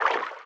Sfx_creature_symbiote_swim_slow_04.ogg